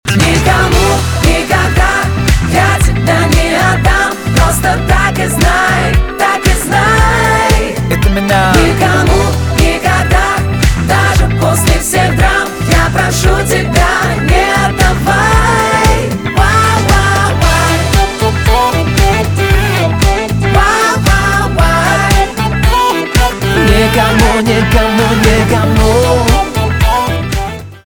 поп
романтические
диско